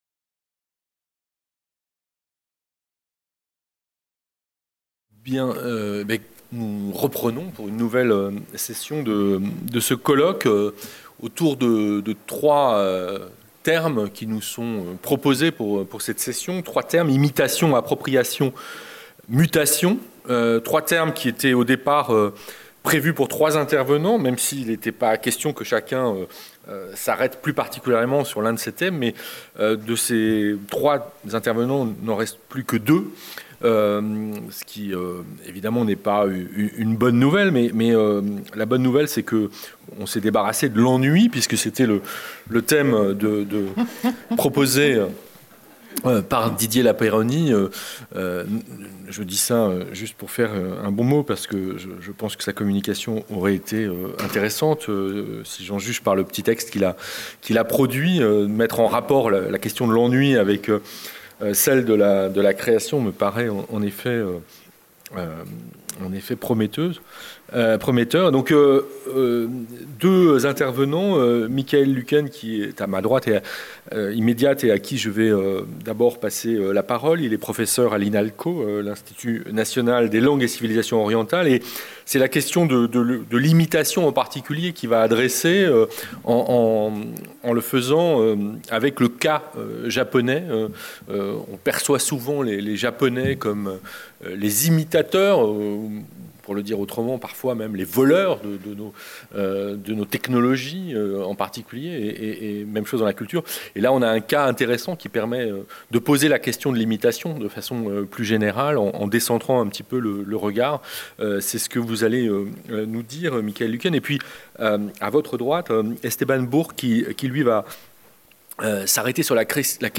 Philharmonie, Salle de conférence 11H15 Imitations